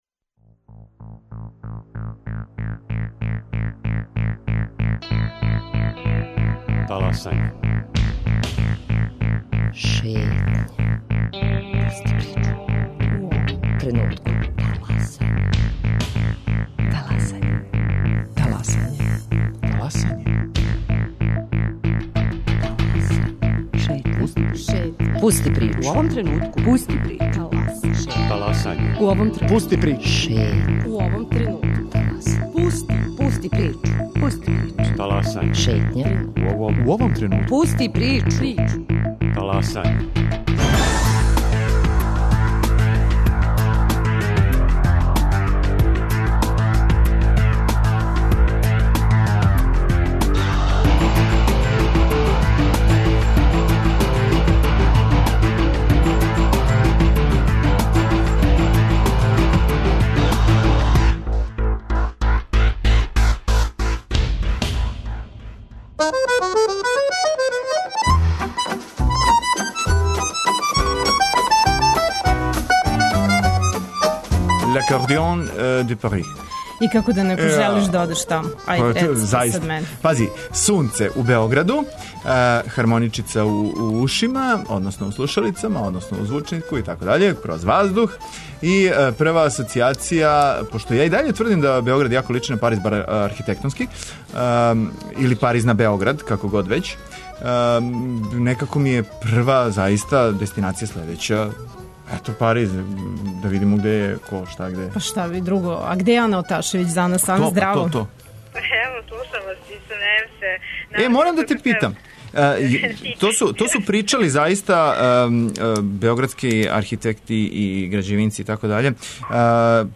Са некима од њих ћемо разговарати телефоном, а гости у студију биће студенти Факултета организционих наука који су победили на европском, и у априлу путују у Њујорк на светско такмичење из пословних студија.